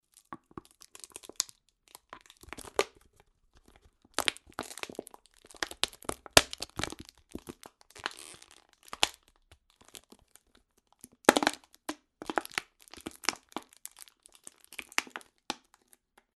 • plastic coffee bottle asmr - crushed - flattened.mp3
Recorded with a Steinberg Sterling Audio ST66 Tube, in a small apartment studio.
plastic_coffee_bottle_asmr_-_crushed_-_flattened_gr4.wav